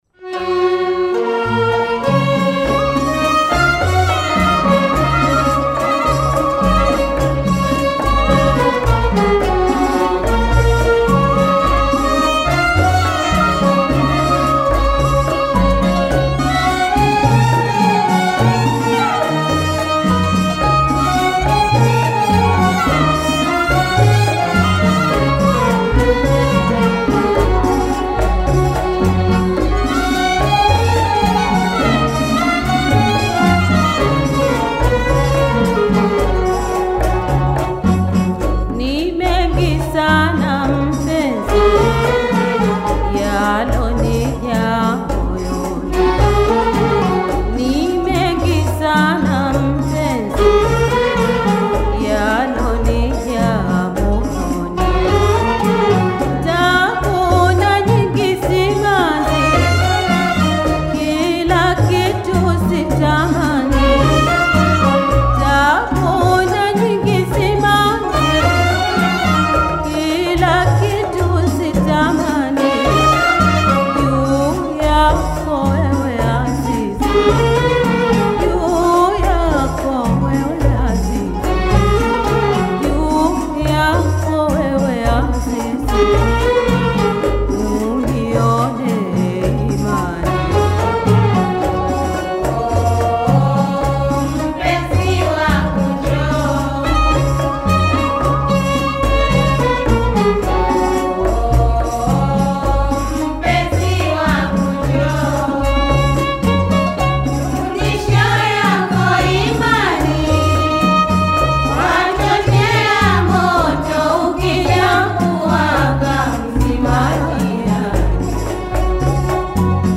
” a captivating serenade that beckons lovers to come closer.